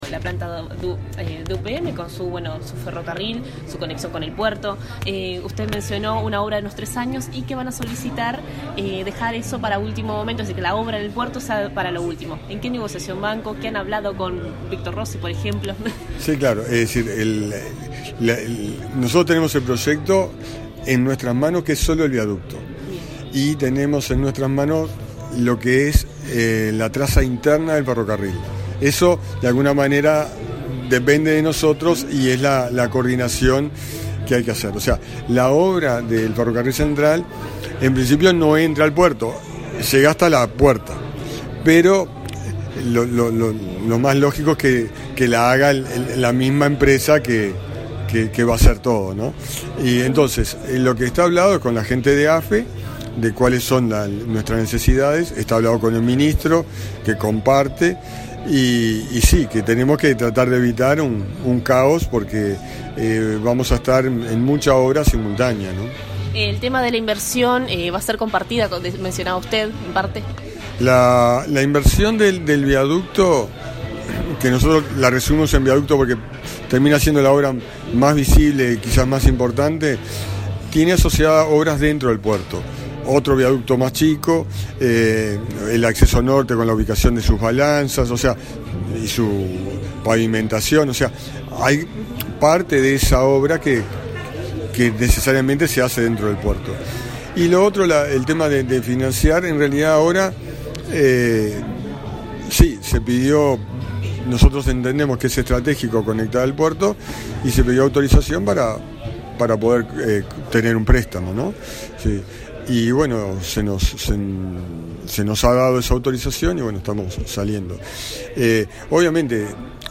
El Presidente de la Administración Nacional de Puertos, Alberto Díaz, expuso sobre «Plan Director 2035», en Cámara Española de Comercio, Industria y Navegación, mostrando los avances en obras y proyectos futuros en los diferentes puertos del país. Informó que la obra ferrocarril central que unirá la futura planta de UPM tendrá una duración de 3 años con un financiamiento compartido a través de un préstamo al Fonplata (Fondo Financiero para el Desarrollo de la Cuenca del Plata).